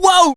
poco_hurt_02.wav